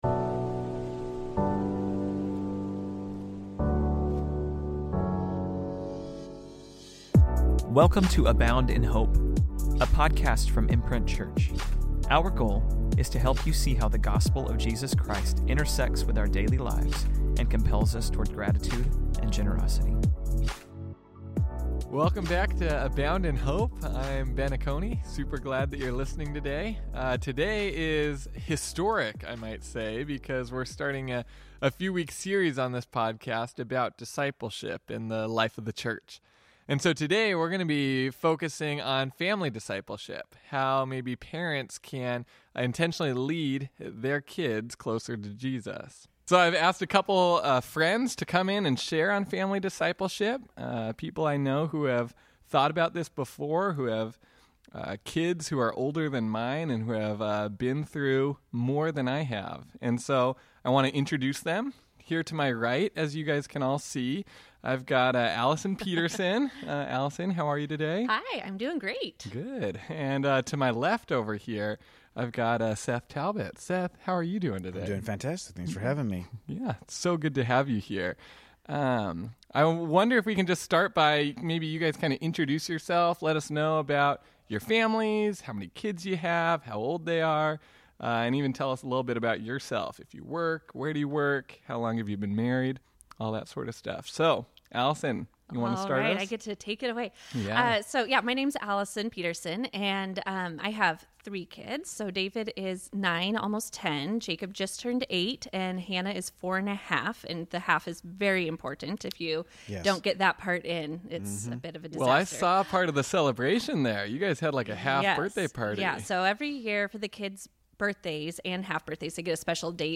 Our first episode in this series is on Family Discipleship. Listen to a conversation